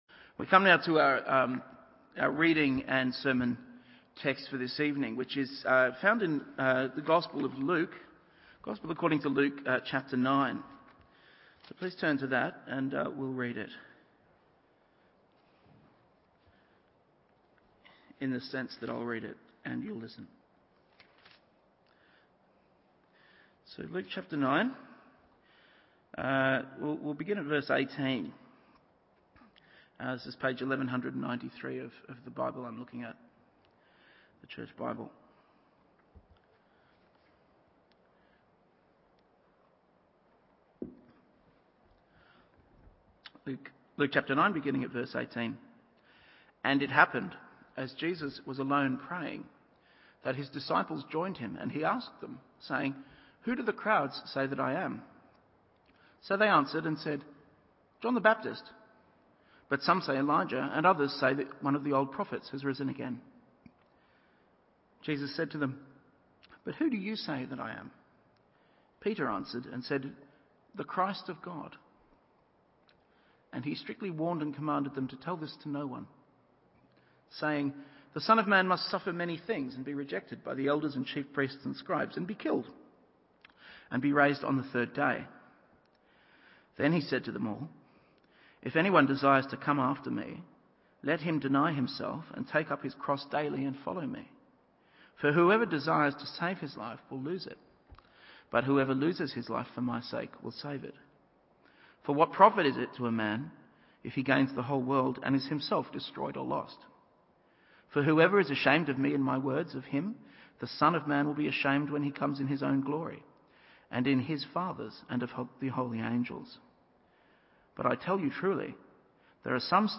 Evening Service Luke 9:28-36…